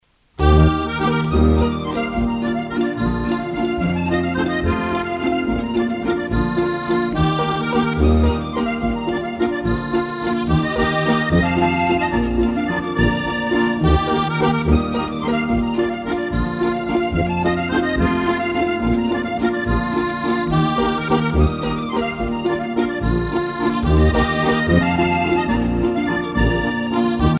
danse : valse ;
Pièce musicale éditée